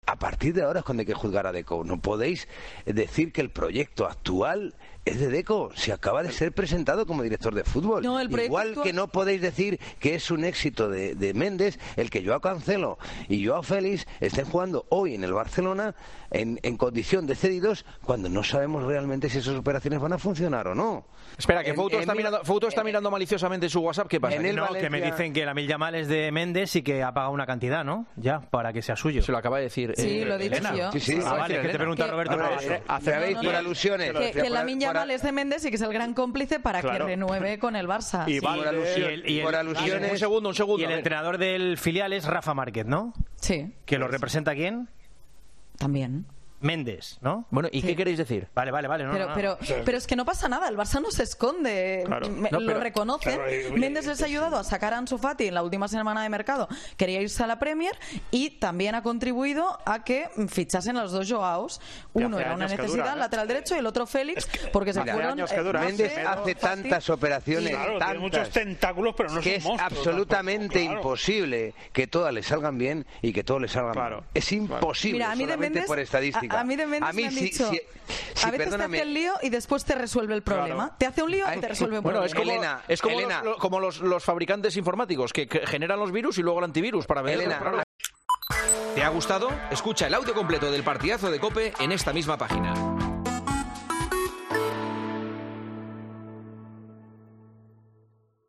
Juanma Castaño explica la relación del Barça y Jorge Mendes con una comparación: "Crea y destruye"